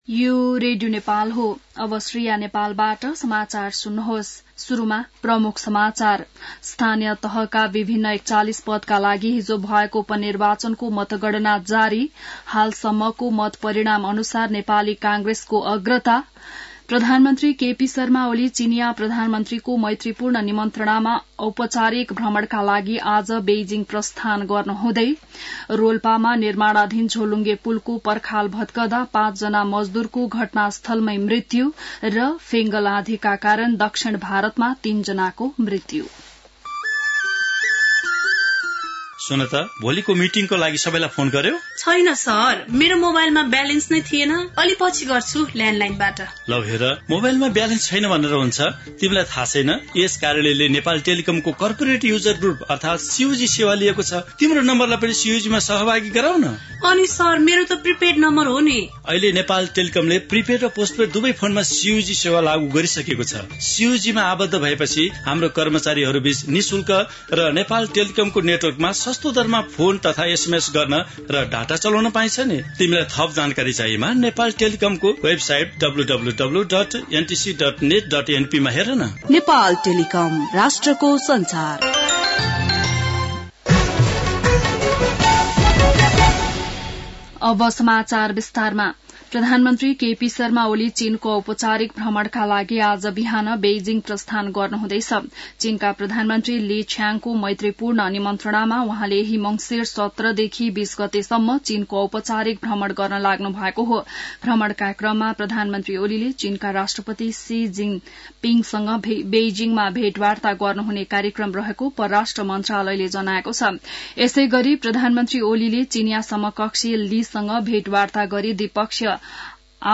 An online outlet of Nepal's national radio broadcaster
बिहान ७ बजेको नेपाली समाचार : १८ मंसिर , २०८१